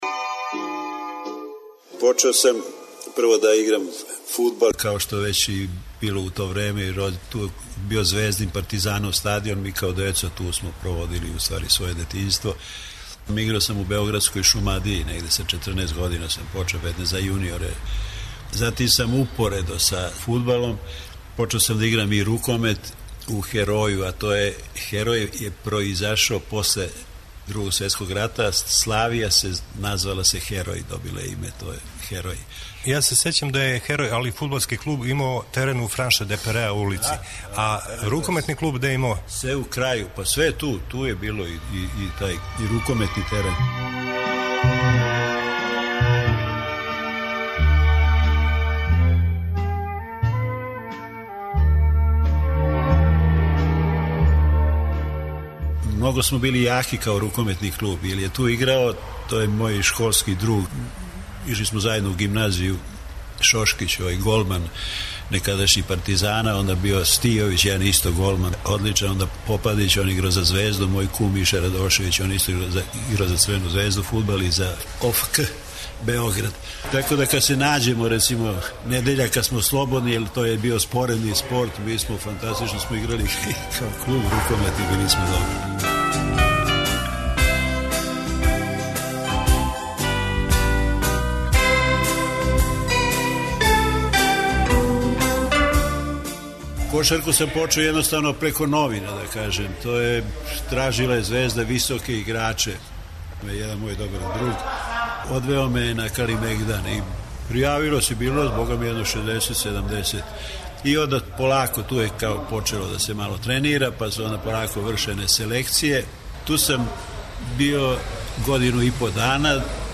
Током летње шеме нашег програма репризирали смо неке од најзанимљивијих разговора са нашим некадашњим спортистима, а од недеље 7. октобра почеће нова серија интервјуа.